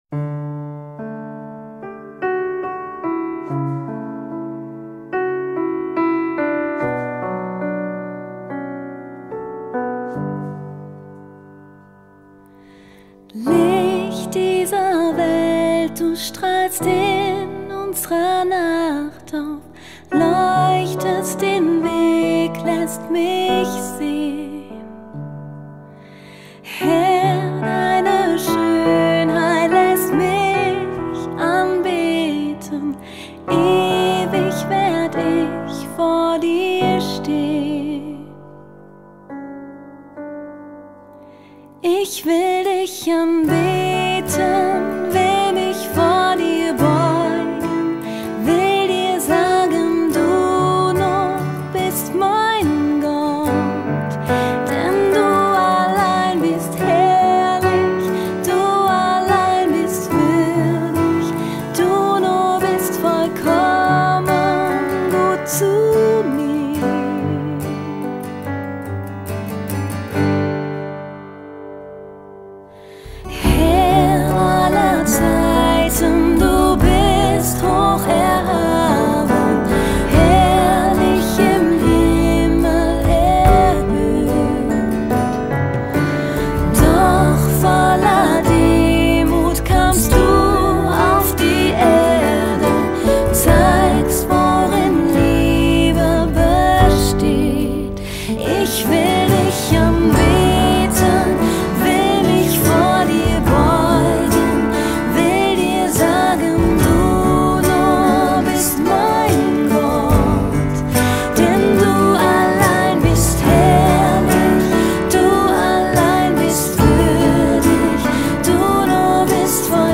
153 просмотра 136 прослушиваний 14 скачиваний BPM: 65